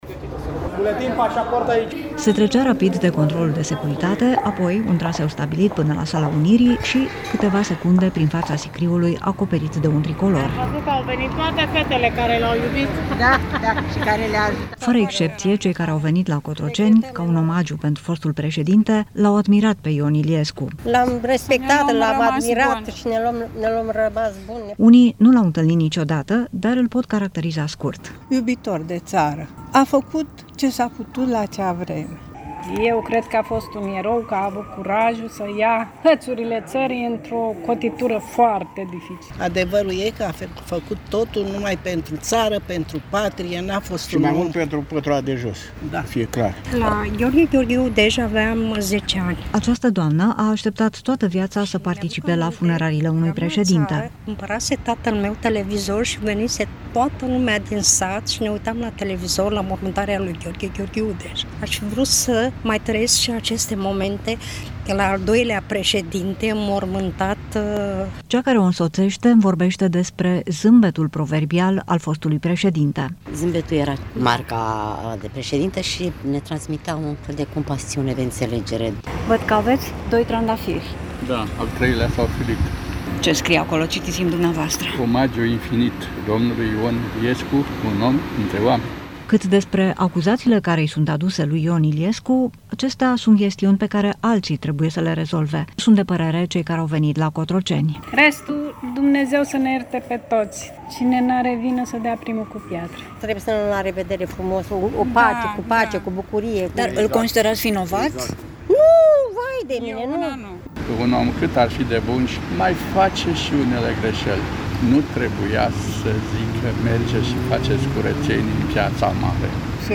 Reportaj: „Au venit toate fetele care l-au iubit” să-i aducă un ultim omagiu lui Ion Iliescu. Cât despre greșeli, „Cine n-are vină să dea primul cu piatra” | GALERIE FOTO
Oameni care l-au admirat pe fostul președinte Ion Iliescu au venit astăzi la Palatul Cotroceni pentru, spun ei, un ultim omagiu.
„Au venit toate fetele care l-au iubit. Da, da, și care le-a ajutat”, au declarat două doamne, pentru Europa FM